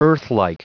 Prononciation du mot earthlike en anglais (fichier audio)
Prononciation du mot : earthlike